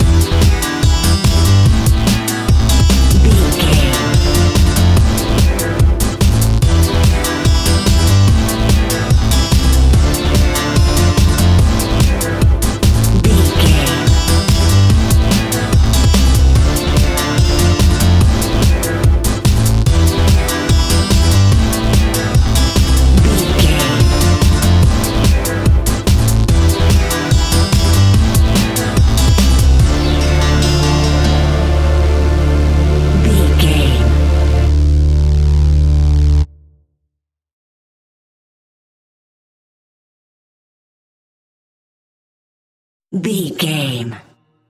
Aeolian/Minor
Fast
driving
energetic
futuristic
hypnotic
drum machine
synthesiser
acid house
uptempo
synth leads
synth bass